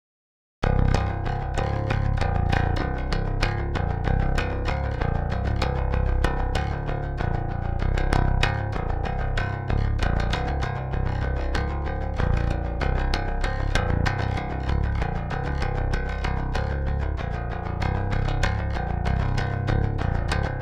Как по-вашему, это брак, или можно работать? Это диайник баса, на записи не смог адекватно оценить количество верха, отсюда и такой стреляющий отстой получился, можно де-кликом подрезать, но есть ли смысл?